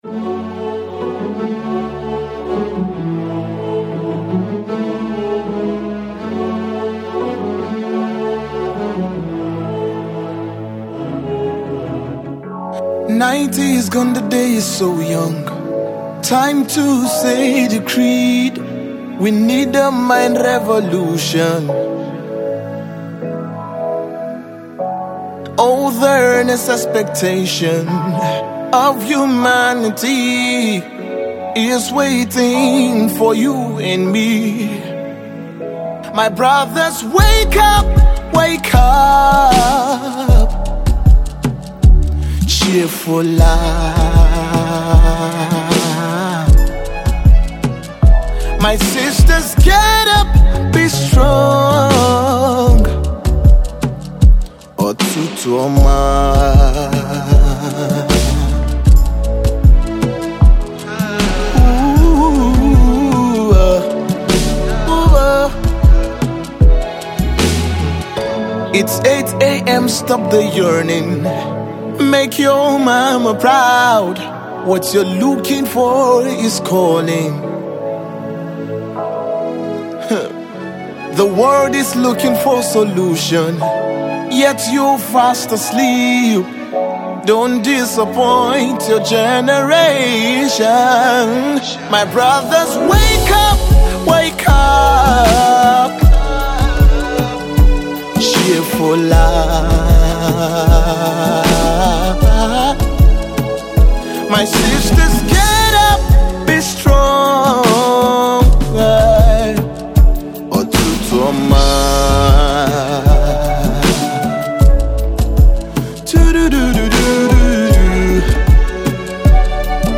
An inspiring song with a little igbo fusion